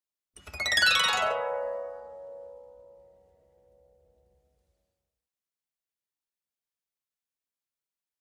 Harp, High Strings Short Descending Gliss, Type 2